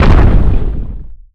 rock_impact_heavy_slam_02.wav